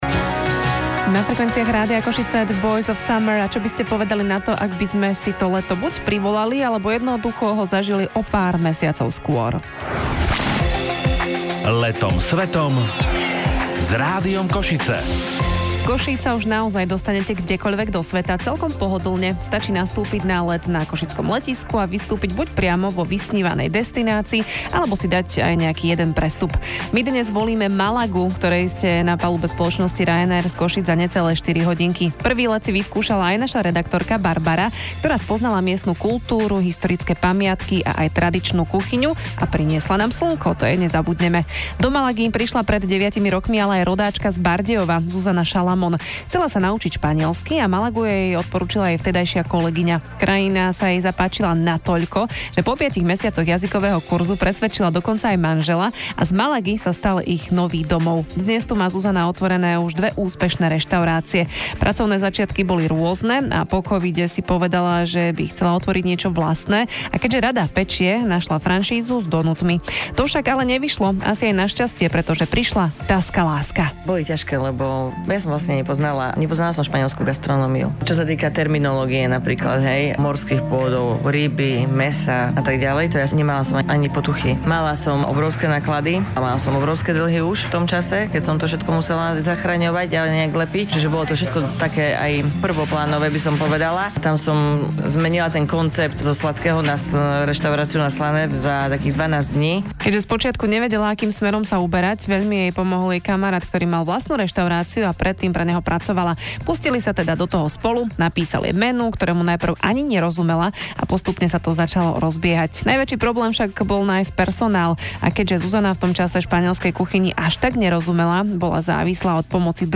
ABRIL 2026/RáDIO KOŠice/Entrevista